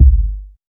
KICK.129.NEPT.wav